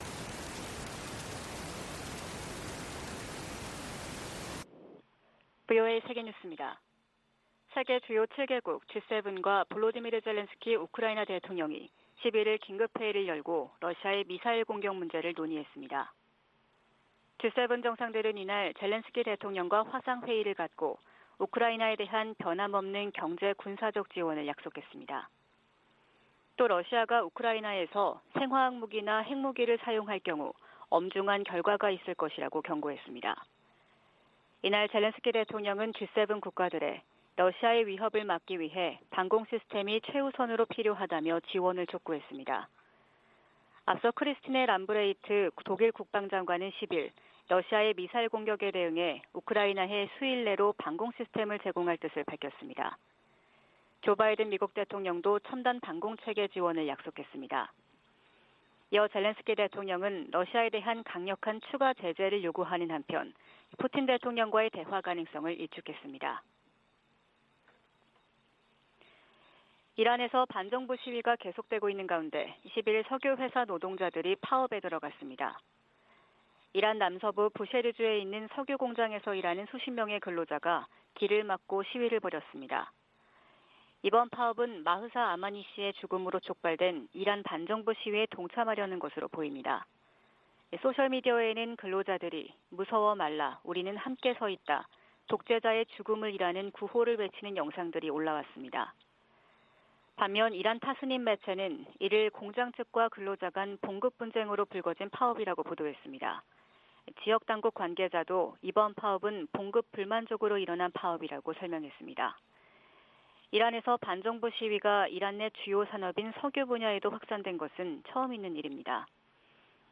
VOA 한국어 '출발 뉴스 쇼', 2022년 10월 12일 방송입니다. 북한이 최근 핵전쟁 훈련을 벌인 것으로 확인되면서 향후 전술핵탄두 실험을 할 가능성이 높아졌다는 관측이 나오고 있습니다.